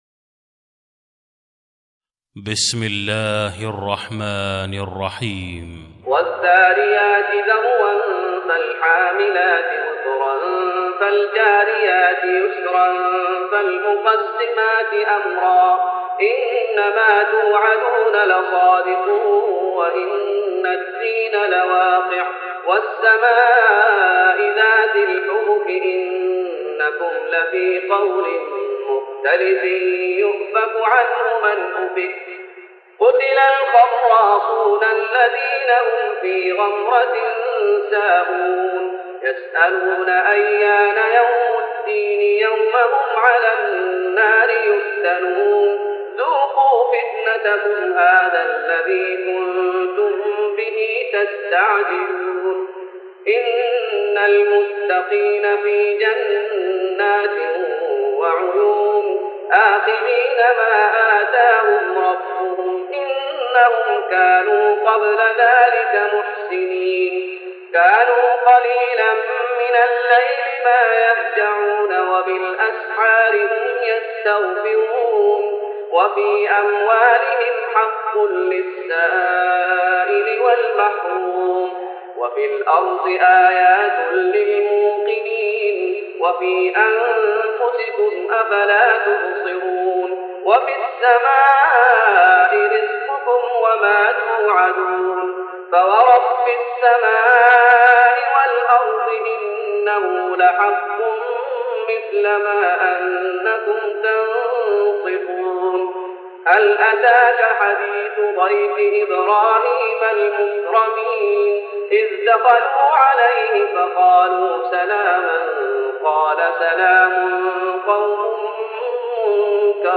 تراويح رمضان 1414هـ من سورة الذاريات Taraweeh Ramadan 1414H from Surah Adh-Dhaariyat > تراويح الشيخ محمد أيوب بالنبوي 1414 🕌 > التراويح - تلاوات الحرمين